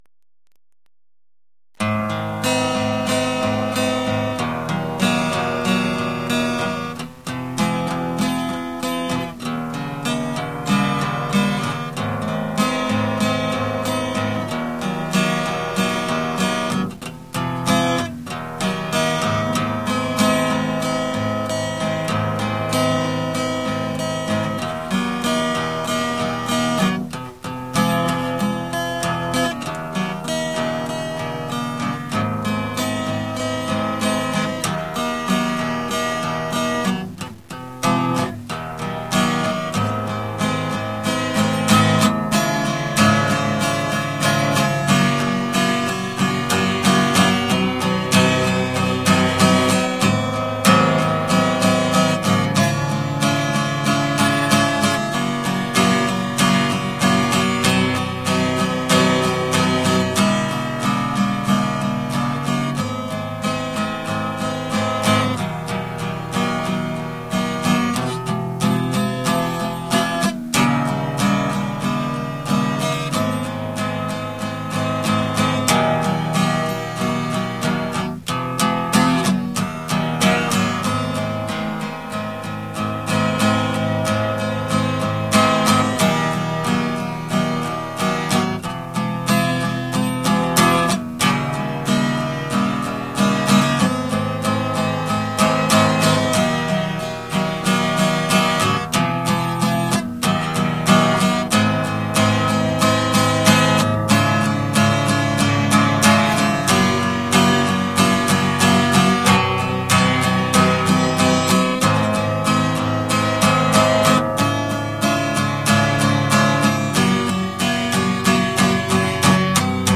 もっと優しく弾きたいのに力が入りっぱなし。